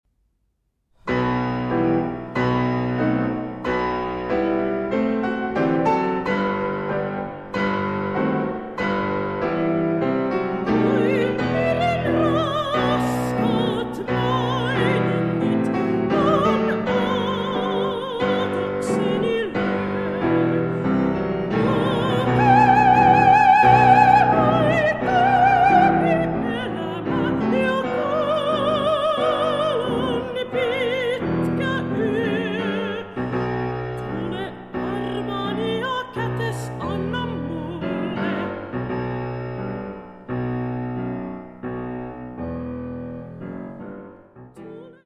Vocalise